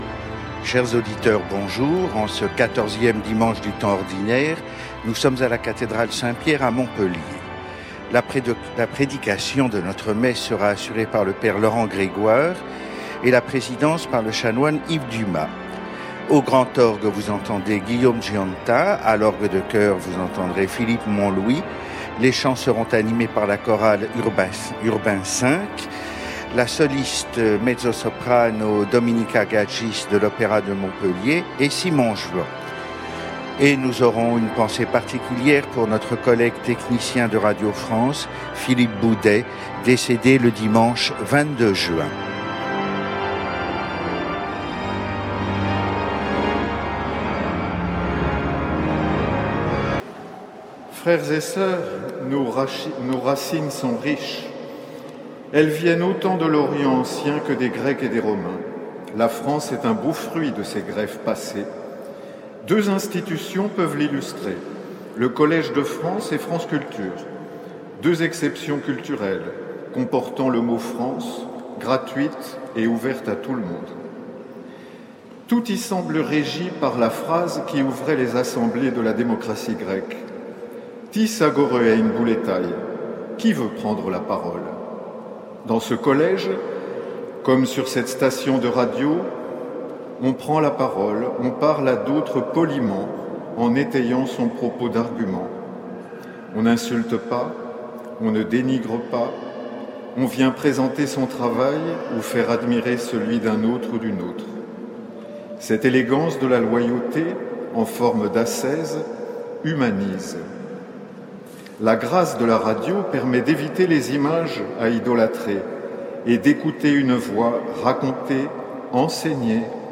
L’enregistrement retransmet l’homélie radiodiffusée depuis la cathédrale Saint-Pierre.